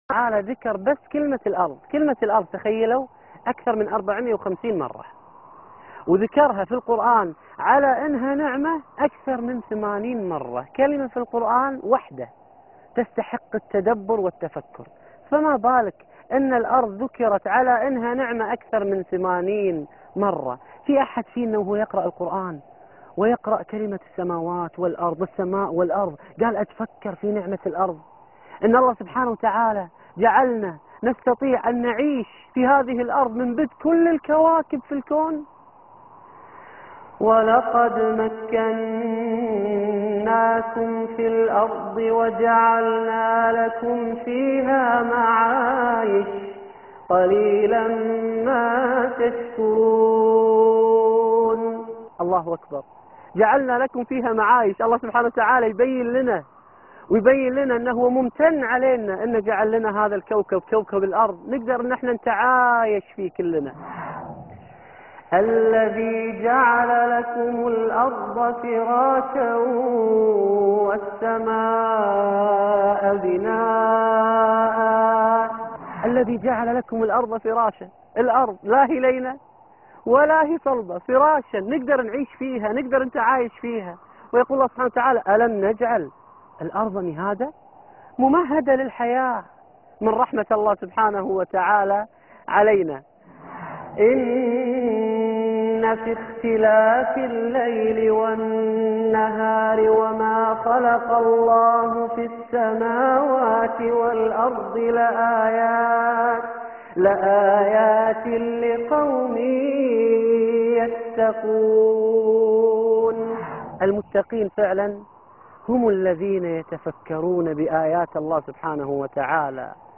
نعمة الأرض (24/8/2011) عبدًا شكورا - القاريء فهد الكندري